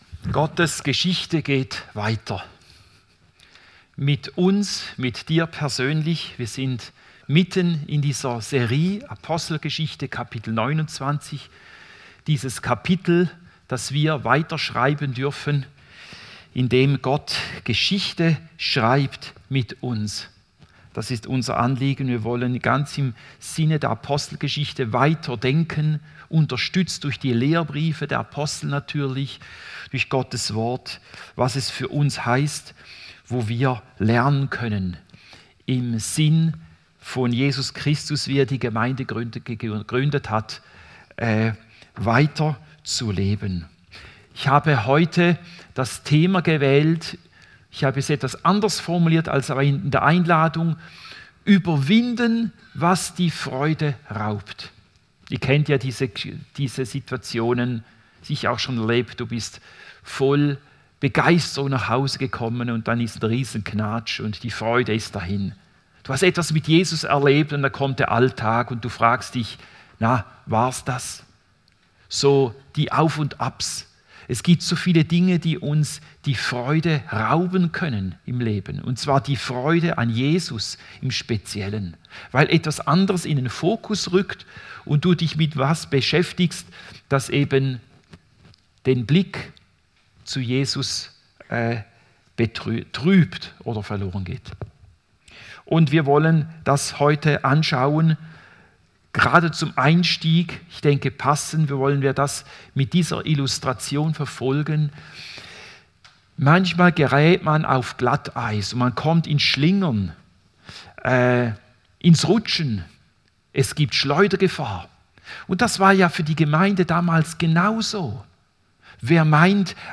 Predigt 15. März 2026